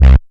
34SYN.BASS.wav